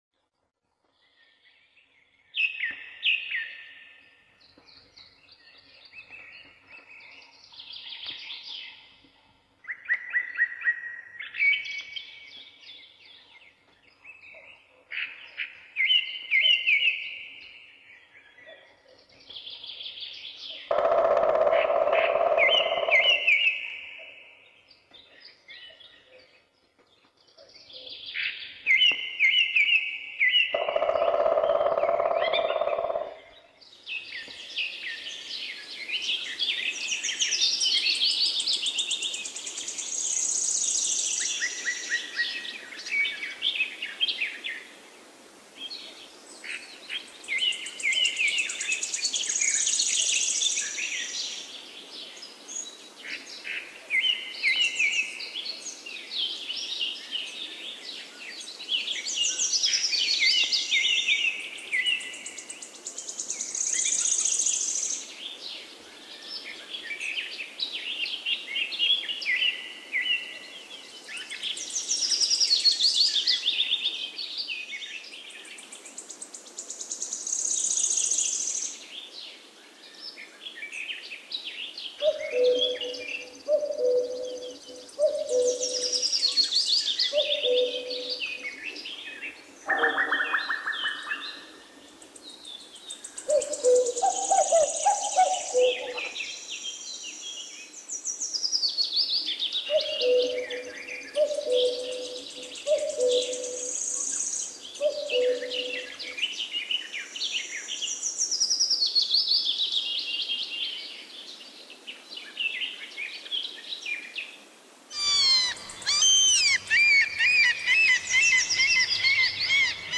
Звуки леса
Звуки утреннего леса России: